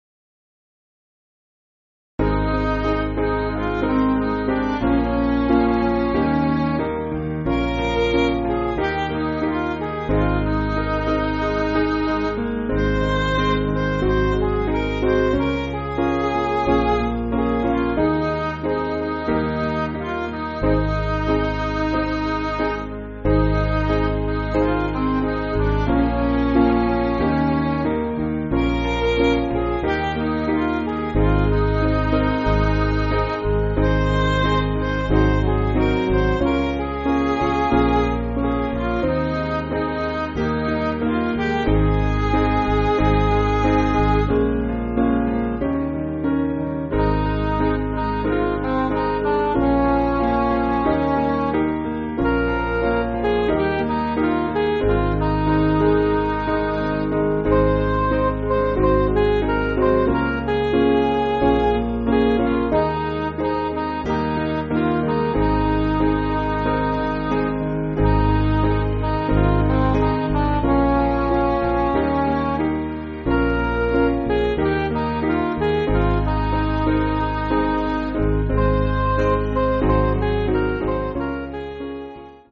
Piano & Instrumental